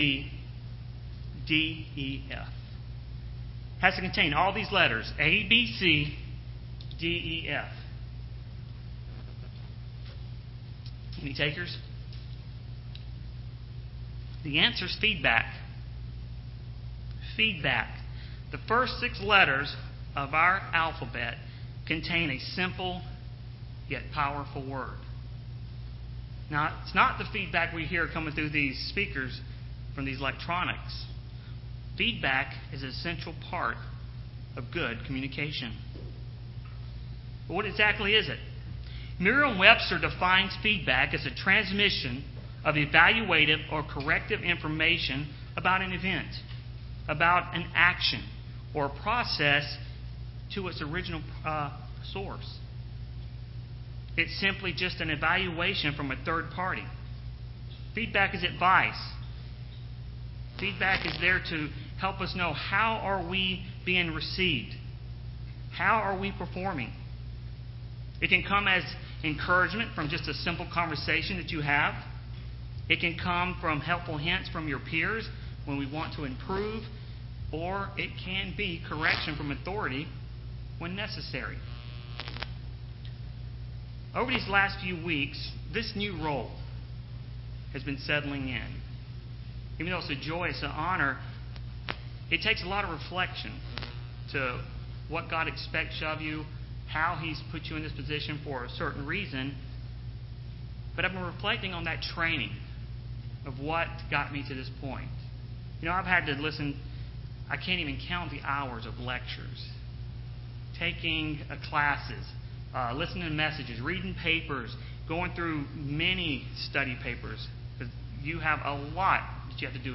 Sermons
Given in Rome, GA